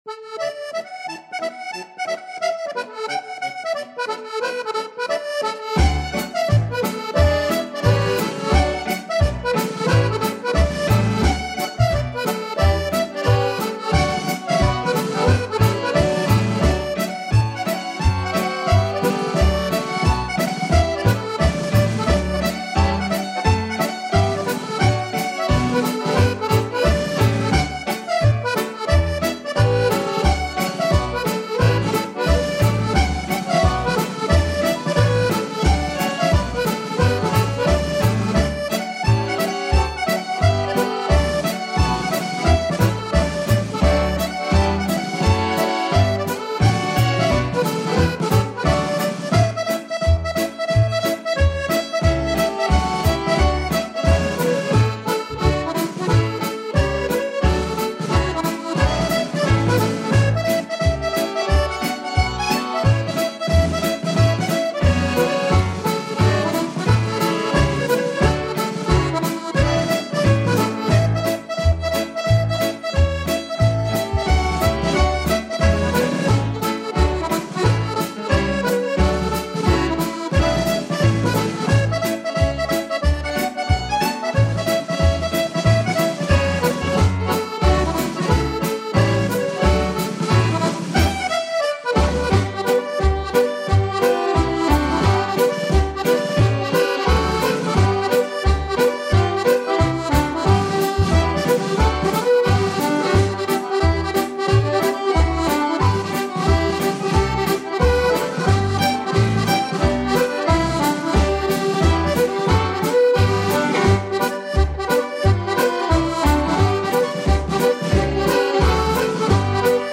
Genre: Traditional Country.